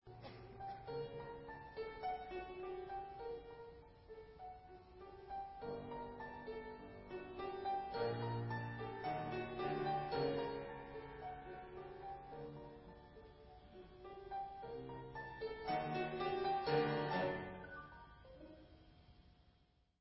Recorded live november 1994